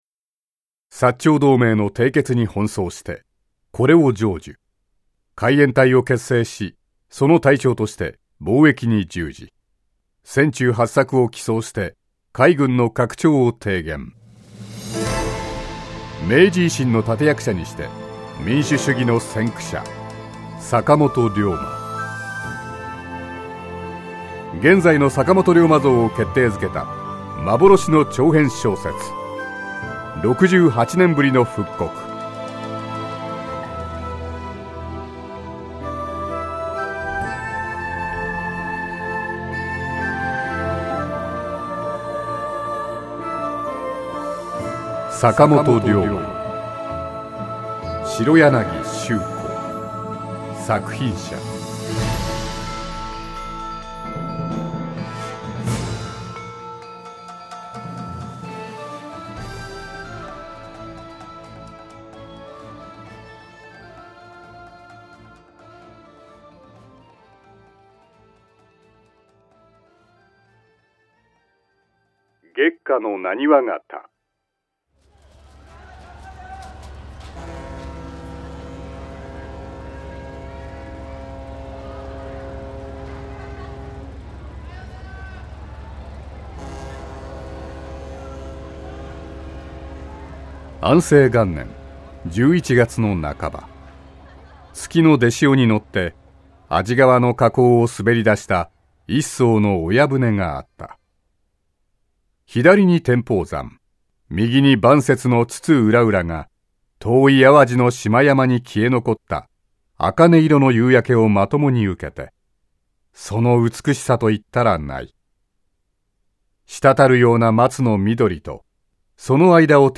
無料 オーディオブックサンプル CD 第五弾 パンローリングで制作している、おすすめ・一押しオーディオブックを ダイジェストとして集めた、配布用無料オーディオブックサンプルCDの 内容をすべて試聴できます。